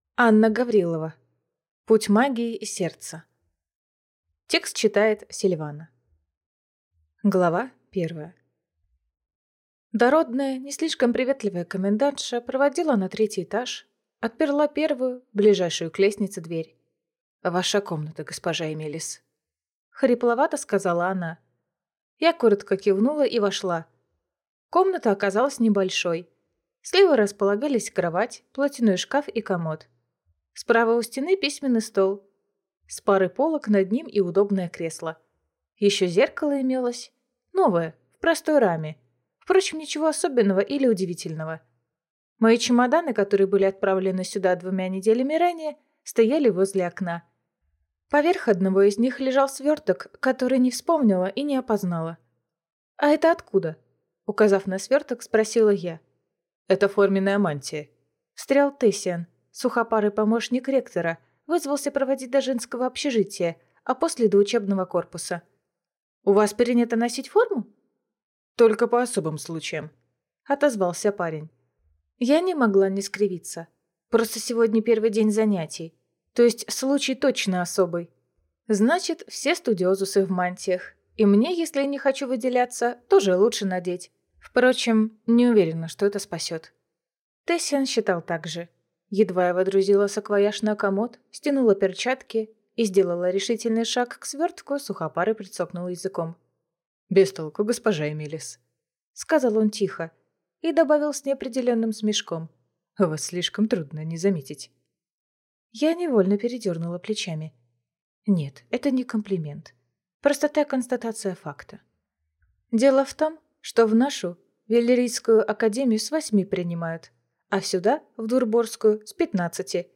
Аудиокнига Путь магии и сердца | Библиотека аудиокниг